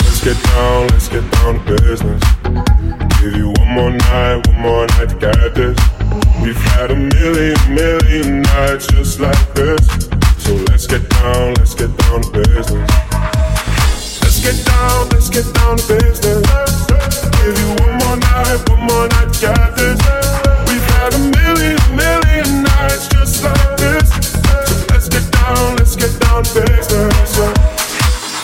Genere: house,deep,edm,remix,hit